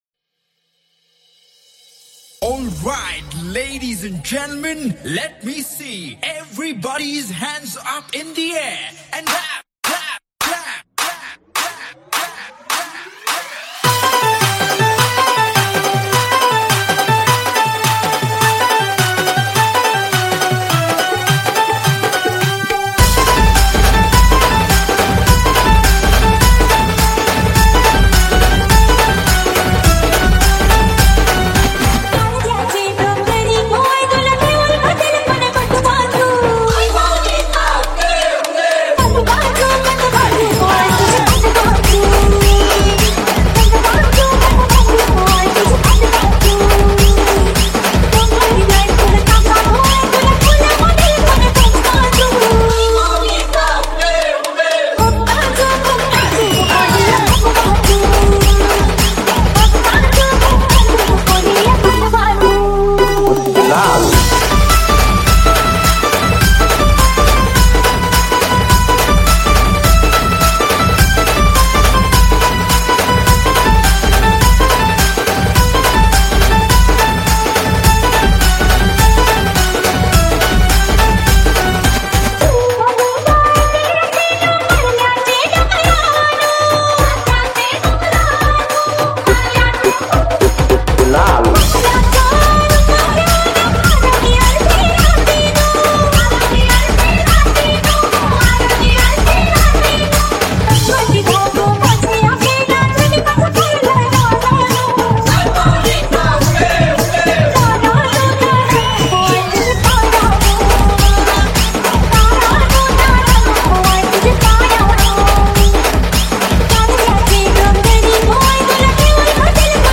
• Category: MARATHI SINGLE